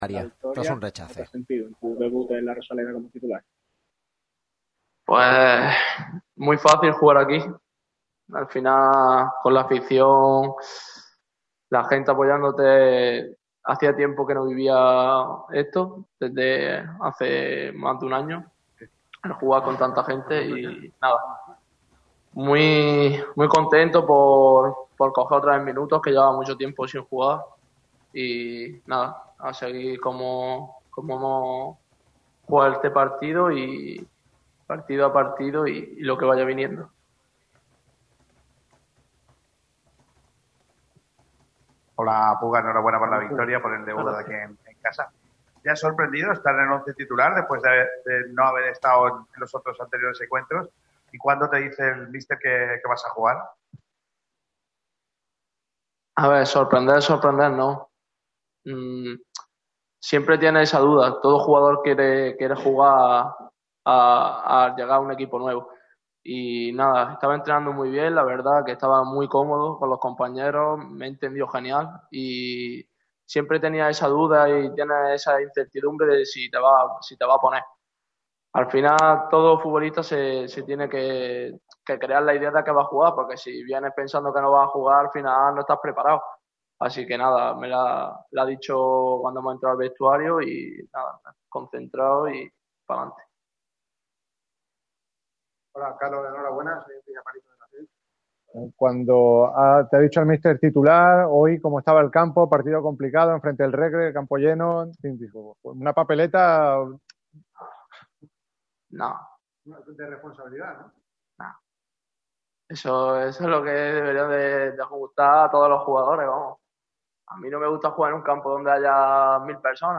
ha atendido a los medios en zona mixta tras la victoria del Málaga frente al Recre